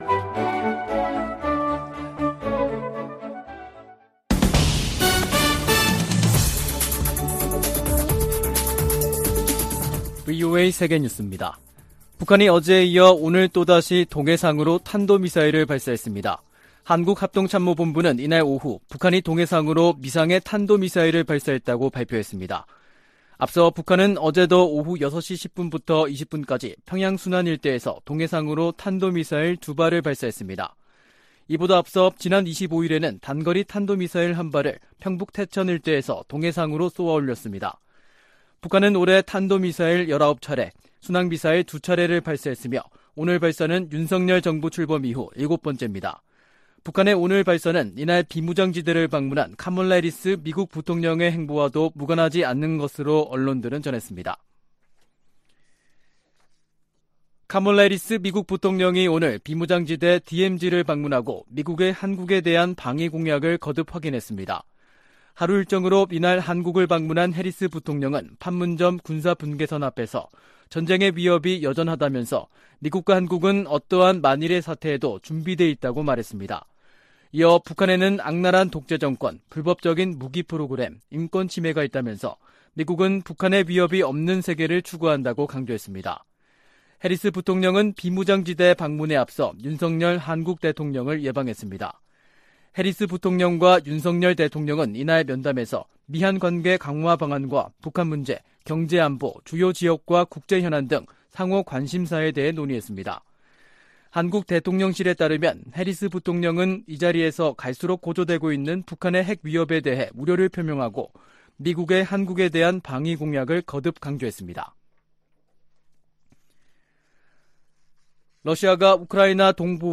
VOA 한국어 간판 뉴스 프로그램 '뉴스 투데이', 2022년 9월 29일 3부 방송입니다. 한국을 방문한 카멀라 해리스 미국 부통령이 윤석열 한국 대통령을 만나 미국의 철통같은 방위공약을 재확인했습니다. 북한이 미국 항모전단이 동해상에서 해상훈련을 하는 중에 탄도미사일을 발사한 것은 핵무력 과시와 김정은에 대해 위협을 멈추라는 메시지가 있다고 전문가들이 분석했습니다. 미 재무부 당국자가 러시아와 거래하는 북한의 무기 공급책을 추적 중이라고 밝혔습니다.